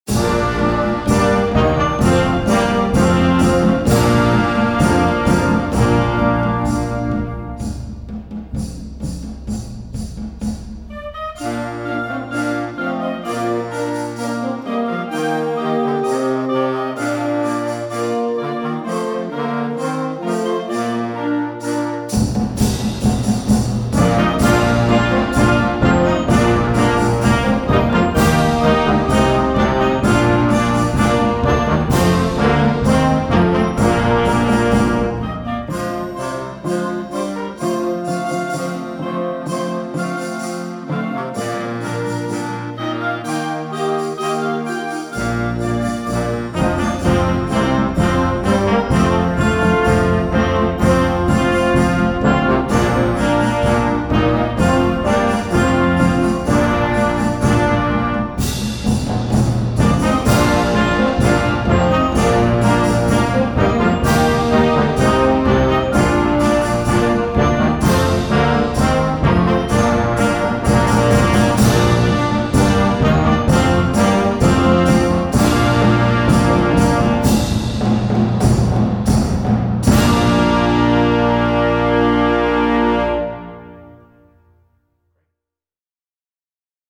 Gattung: Konzertwerk für Jugendblasorchester
Besetzung: Blasorchester
Diese temperamentvolle und authentische Bearbeitung